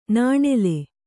♪ nāṇele